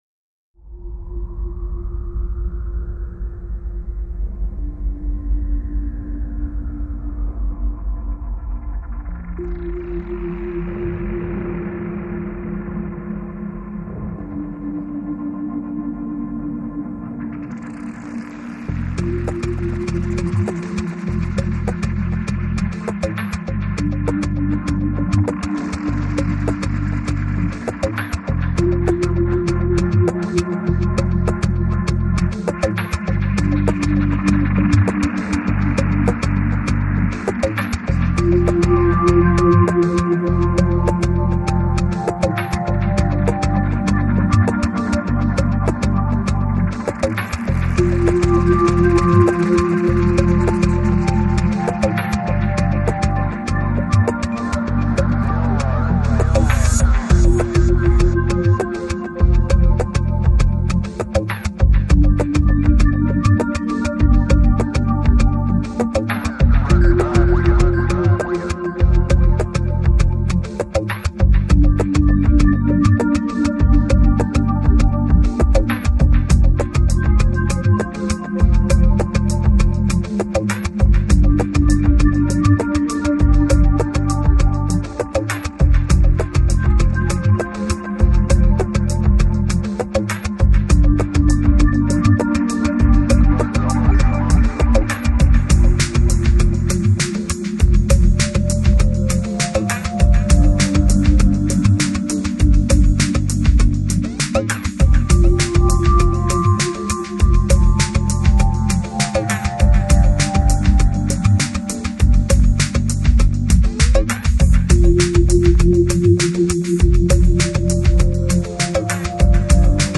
Жанр: Downtempo, Ambient